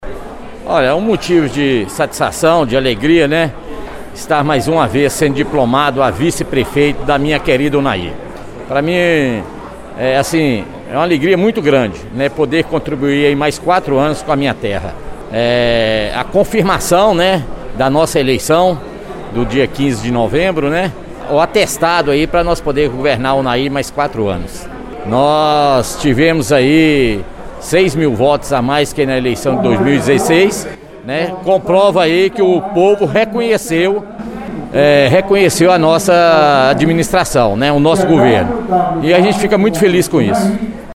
A reportagem da Rádio Veredas ouviu alguns dos personagens deste evento que consolidou a democracia nos dois municípios.